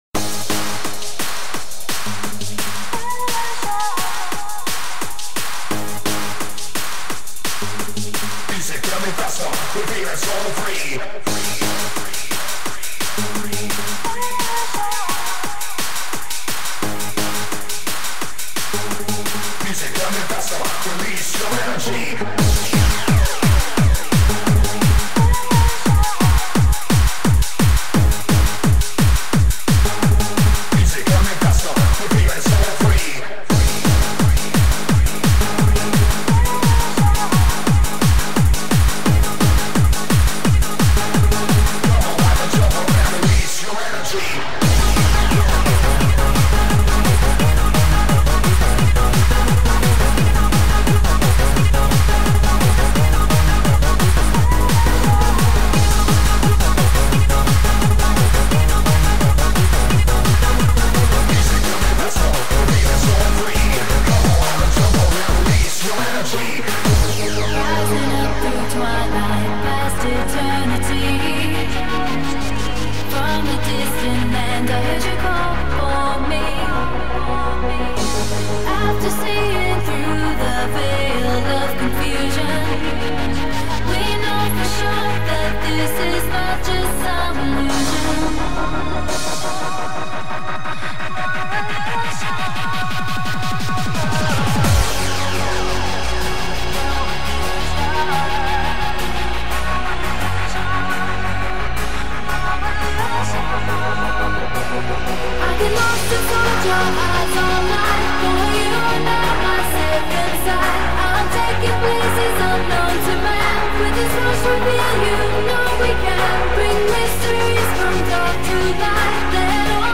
Been way to deep on a happy hardcore phase as of late.
really nice sounding retro track